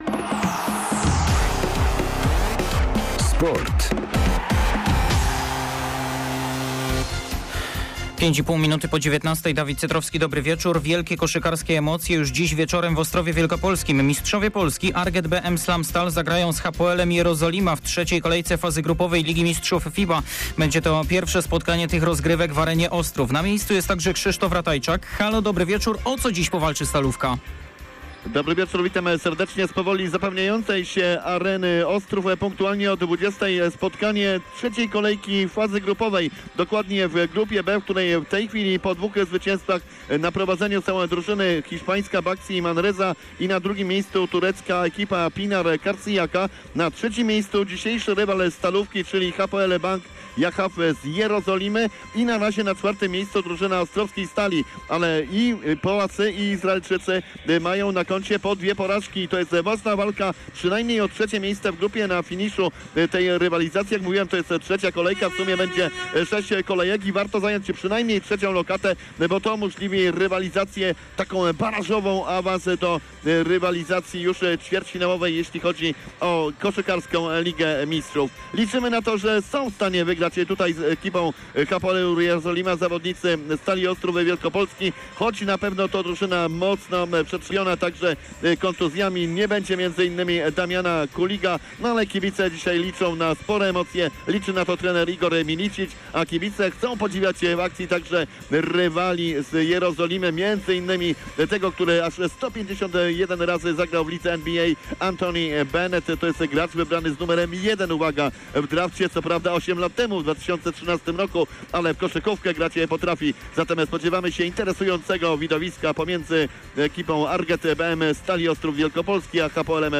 27.10.2021 SERWIS SPORTOWY GODZ. 19:05